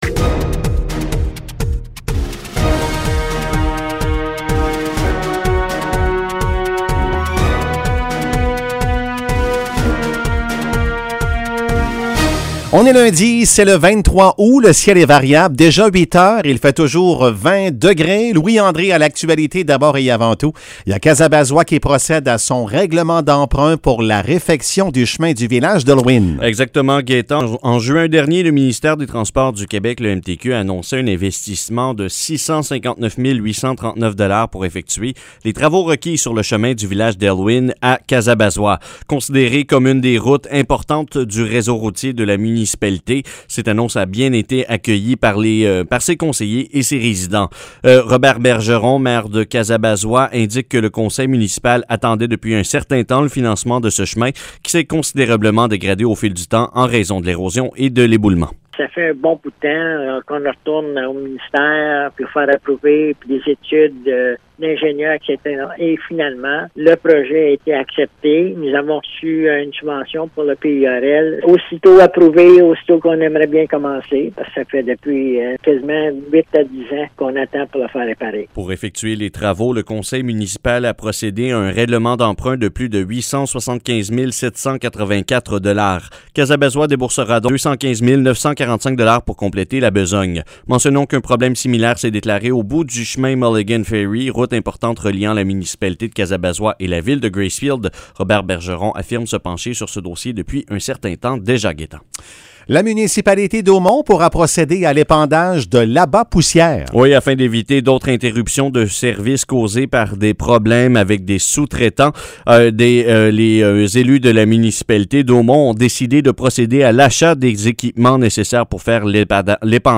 Nouvelles locales - 23 août 2021 - 8 h